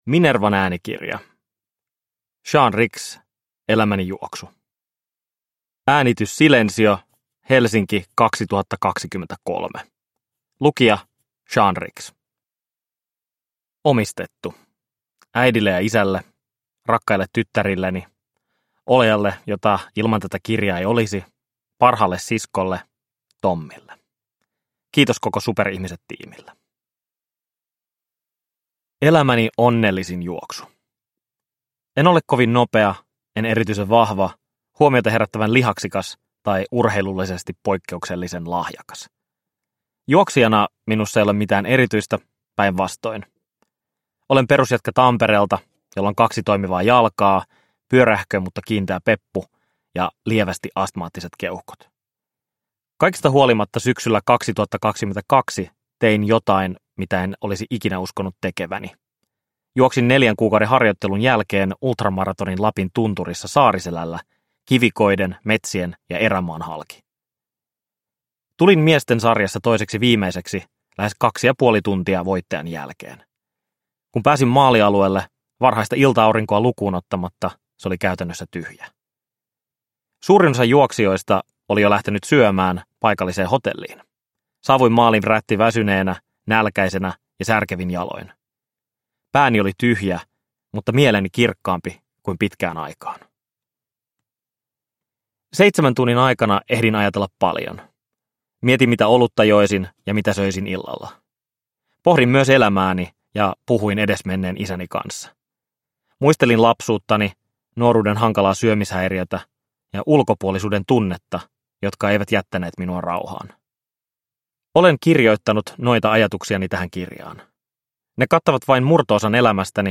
Elämäni juoksu – Ljudbok – Laddas ner
Uppläsare: Sean Ricks